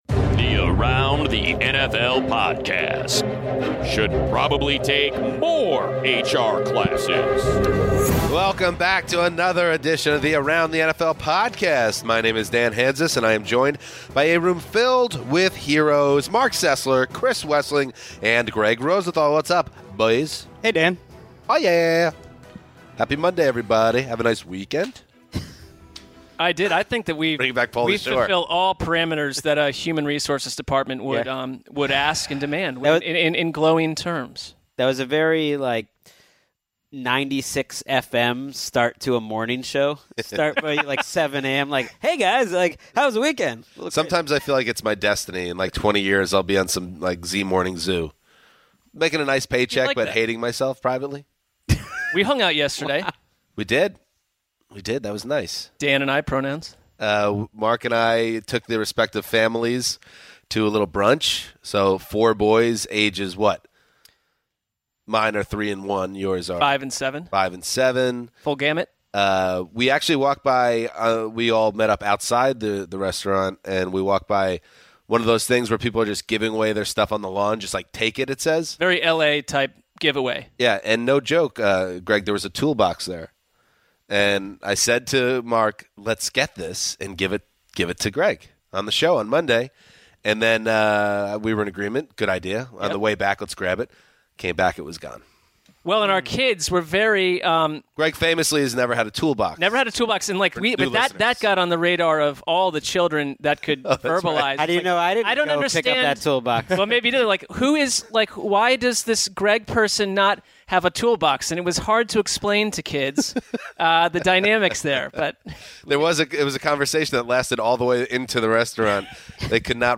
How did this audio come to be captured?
assemble in-studio before heading out to the NFL Combine for the rest of the week.